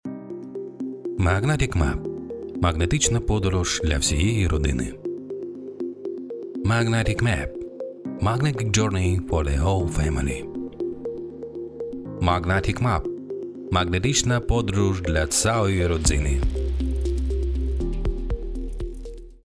Проба голосу (реклама,ролик)